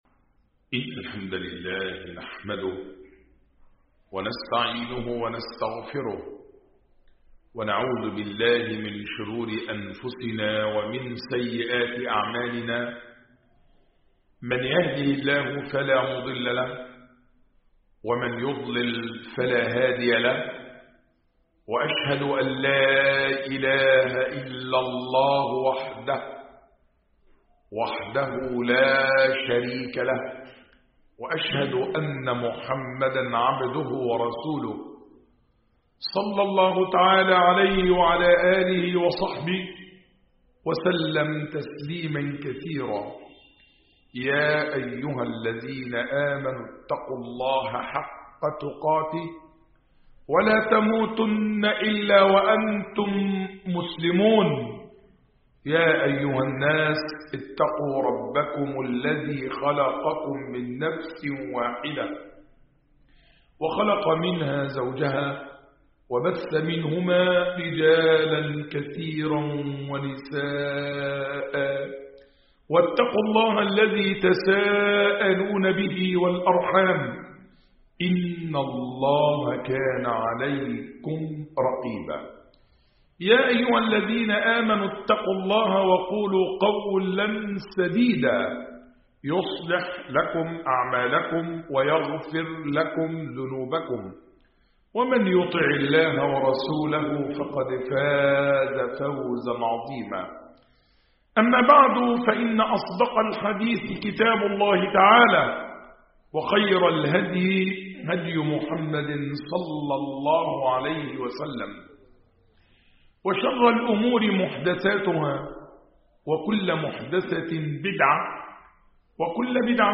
خطب الجمعة والأعياد